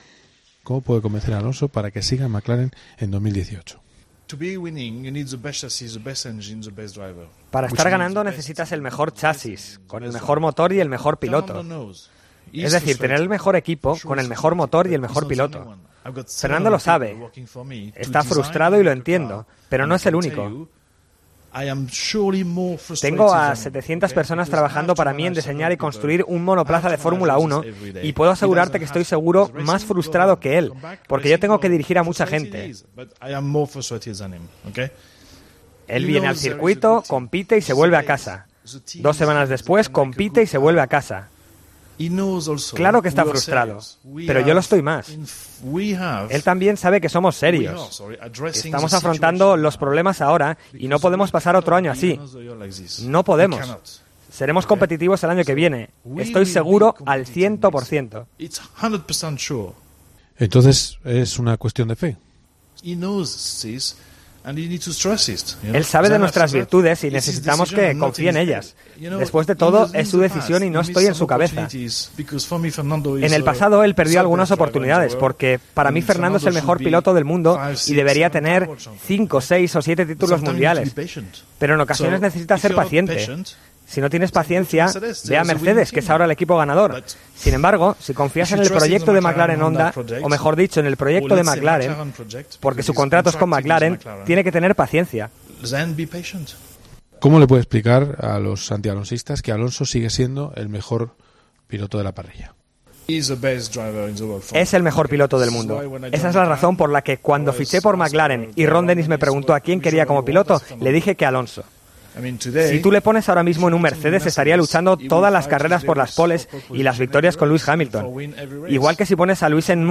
Entrevista en El Partidazo de COPE
Eric Bouiller, jefe de McLaren Honda habla para El Partidazo de Cope. El francés aún cree poder retener al asturiano: "Entiendo su frustración, pero yo lo estoy aún más".